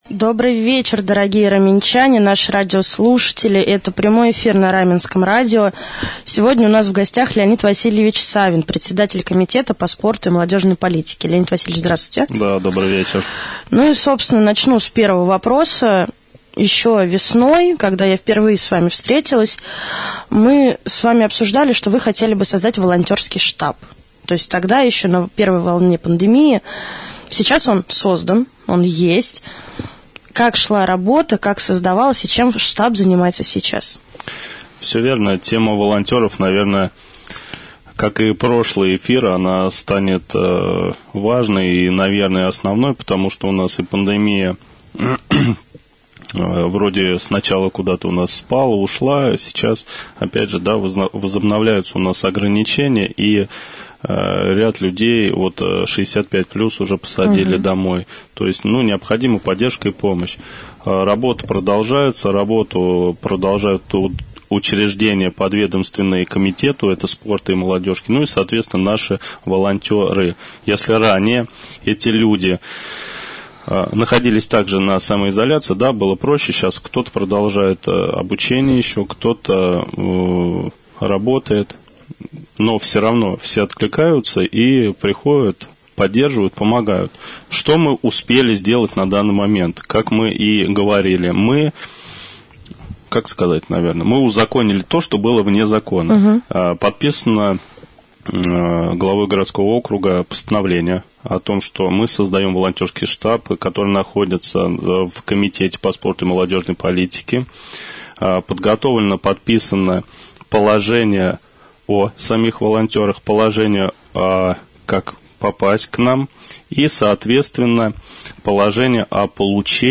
Леонид Васильевич Савин, председатель Комитета по спорту и молодежной политике администрации Раменского г.о., стал гостем прямого эфира на Раменском радио 12 ноября.
prjamoj-jefir.mp3